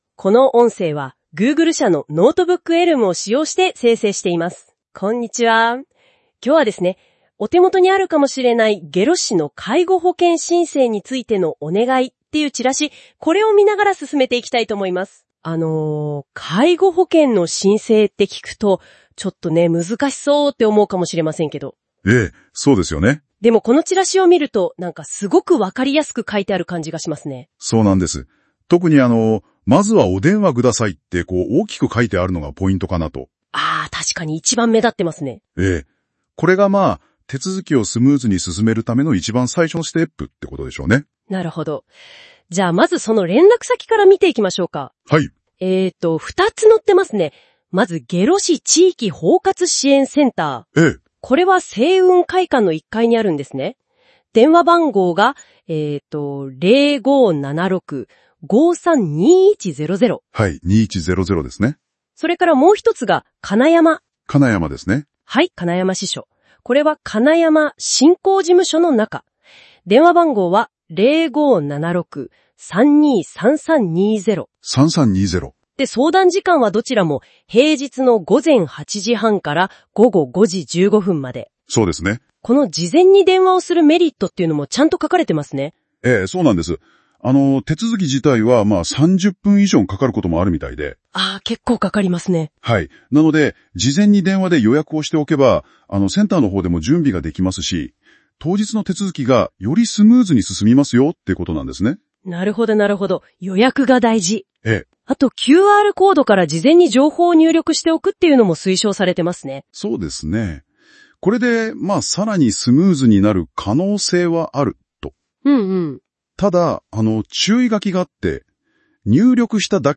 なお、音声による説明も聞くことができます。
介護保険申請についてのお願い（Notebooklmによる音声概要） [その他のファイル／1.22MB]